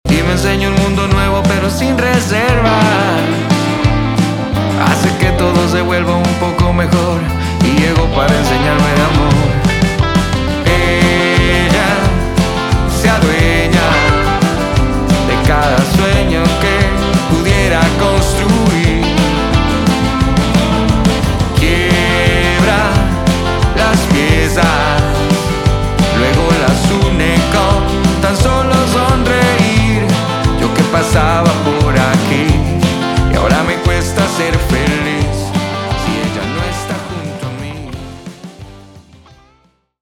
Género: Rock / Pop Rock.